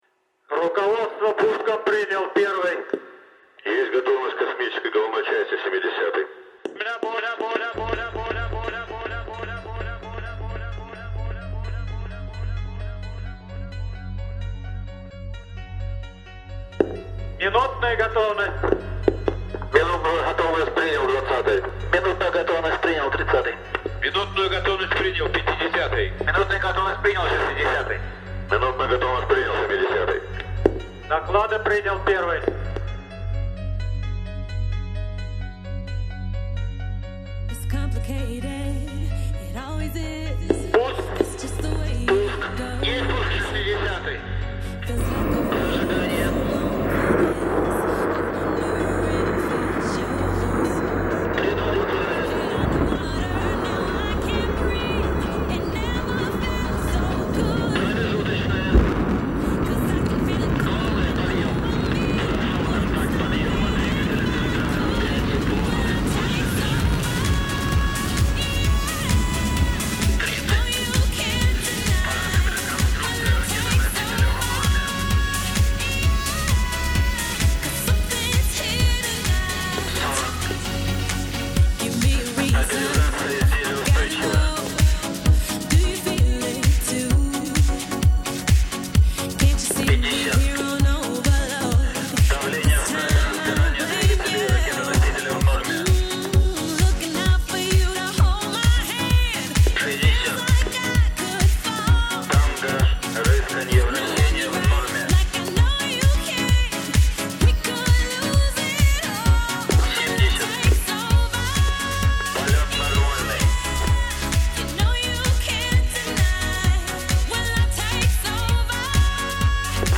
Ce compte rendu est diffusé par haut-parleur sur le pas de tir pour l'équipe de préparation, retransmis au poste d'observation pour les invités et les touristes, et également diffusé à Samara, Moscou et d'autres lieux pour les personnes intéressées [comme nous-mêmes].
Pour ceux qui veulent on peut écouter l'enregistrement audio du lancement de Globalstar-2 par le lanceur Soyouz (attention il y a de la musique en fond, pas si en fond que ça, pour combler les moments où il n'y a pas d'annonce) c'est